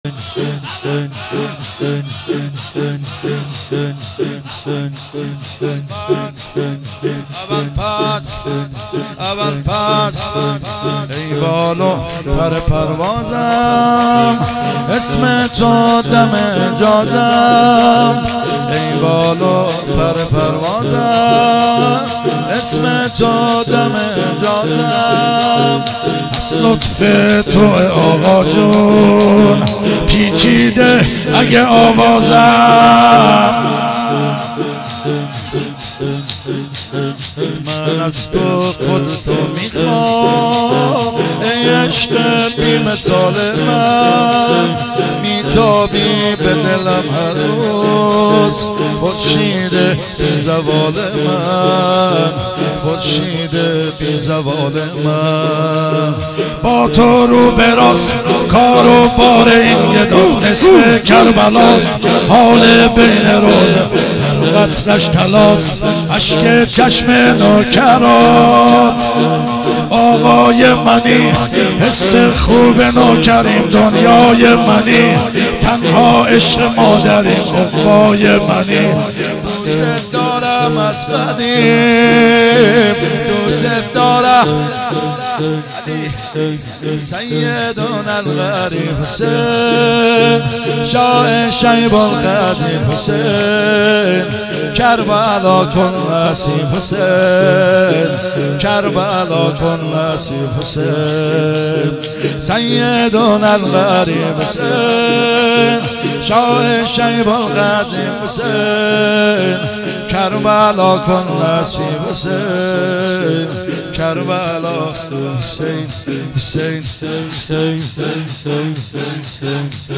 مداحی شور
شب اول فاطمیه دوم 1397